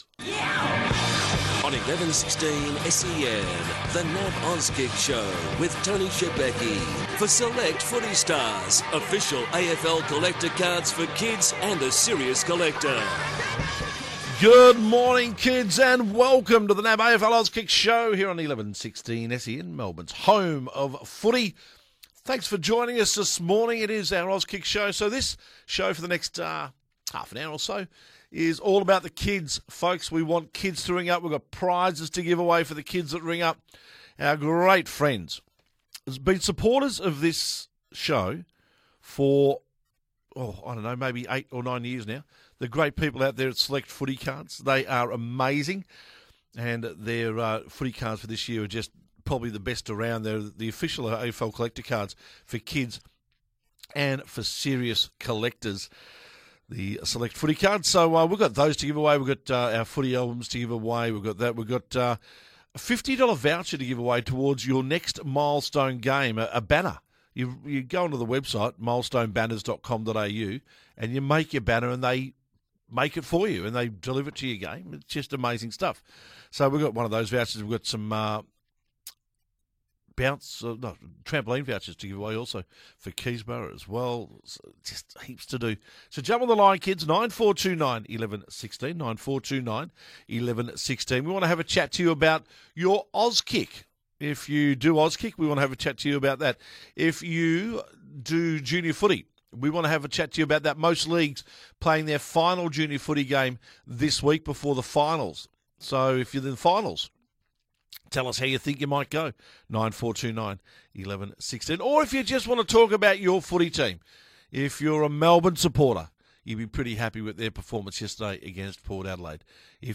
takes all your calls on The Auskick Show!